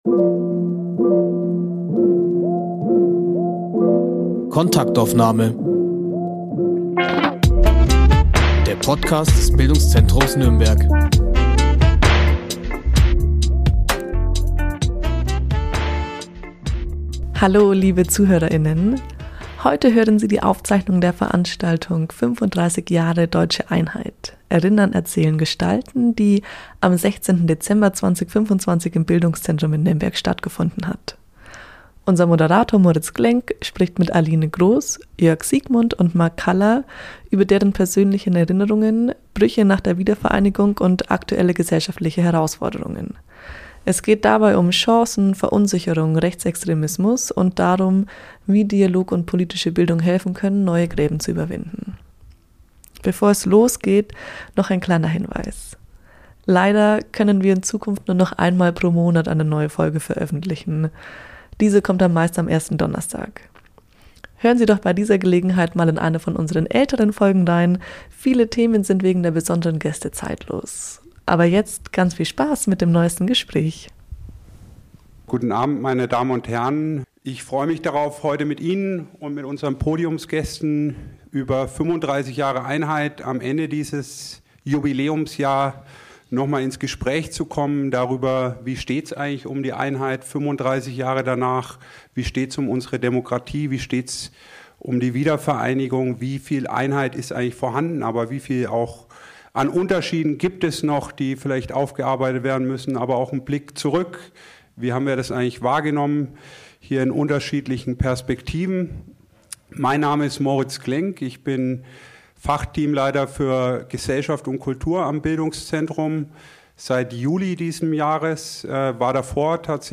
35 Jahre Einheit: persönliche Erinnerungen, politische Brüche und offene Wunden. Eine ehrliche Debatte über Ost, West und die Zukunft der Demokratie.